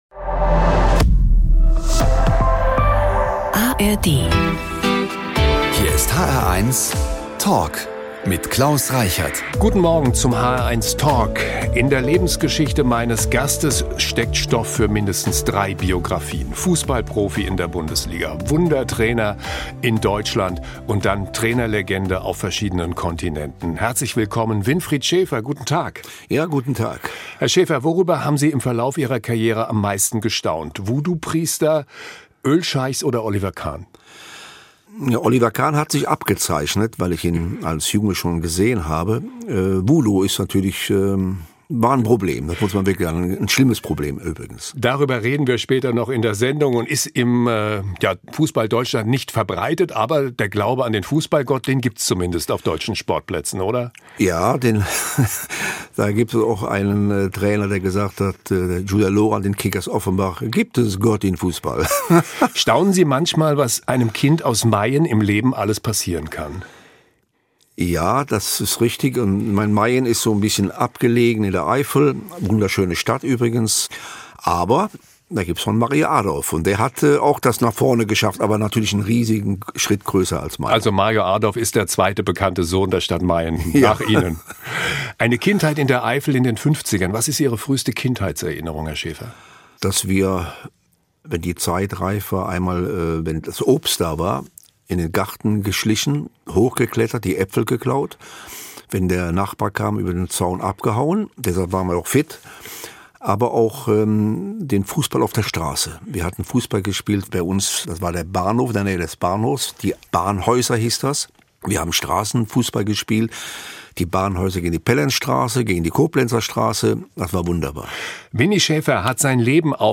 Überraschende Einblicke und spannende Ansichten: Die hr1-Moderatoren im sehr persönlichen Gespräch mit Prominenten.
… continue reading 309 эпизодов # Faszinierende Menschen # Hr1 # Hessischer Rundfunk # Interviews # Talk # Interview # Gesellschaft # Gespräche